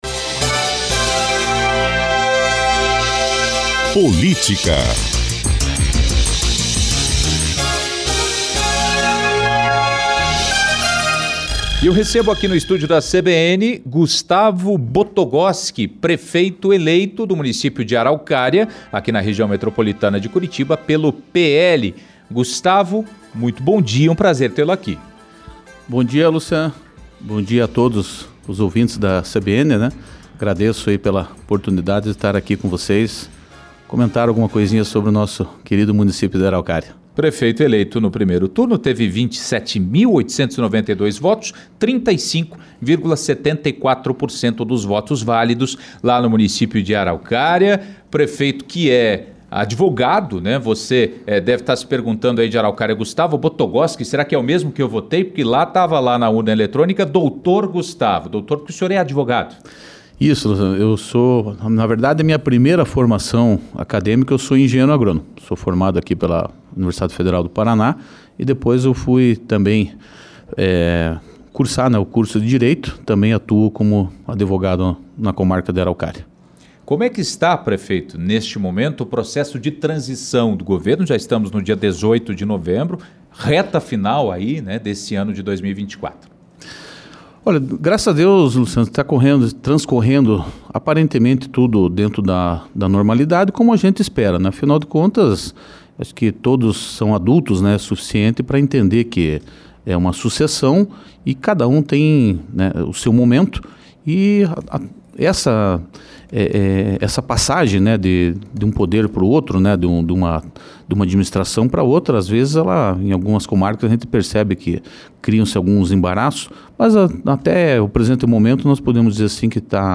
O prefeito eleito de Araucária, na região metropolitana de Curitiba, Gustavo Botogoski (PL), visitou a CBN Curitiba na manhã desta segunda-feira (18).